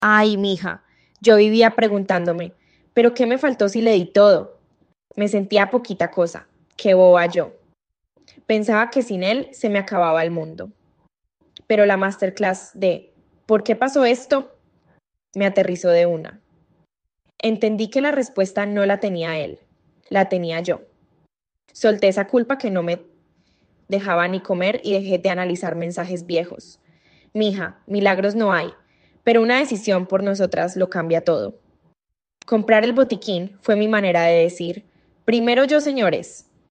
Testimonio-4-1-1.mp3